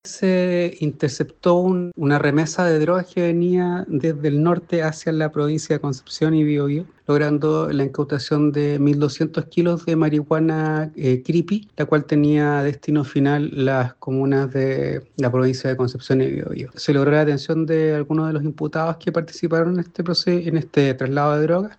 Por su parte, el fiscal de Análisis Criminal, Felipe Calabrano, precisó que la sustancia incautada corresponde a marihuana del tipo “cripy” y confirmó que los imputados serán formalizados en Concepción.